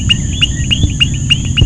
La Ranita de Labio Blanco
La Ranita de Labio Blanco no es un Coqu� y a diferencia de estos tiene una etapa de renacuajo (larva acu�tica). Produce una voz que la gente asocia con un Coqu�.